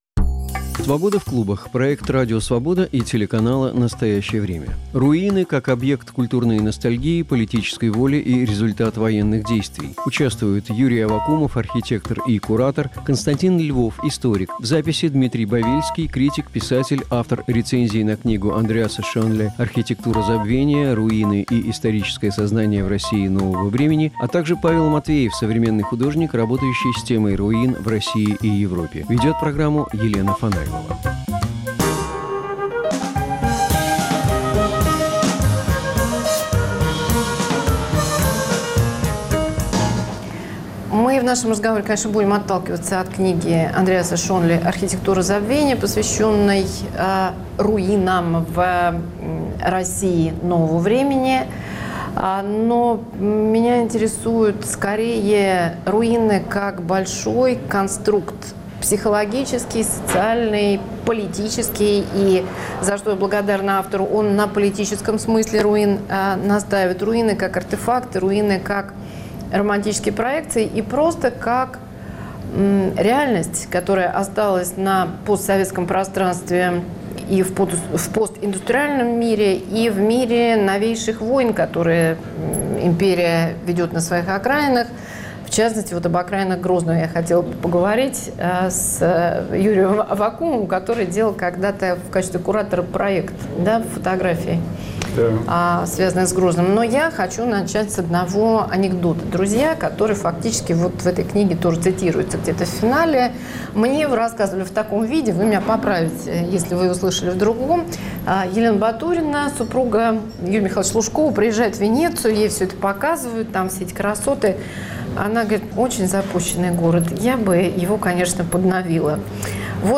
Руины в России: "вспомнить все" или "приказано выжить"? С архитектором, историком, художником и критиком - о политике и практике руинированных зон.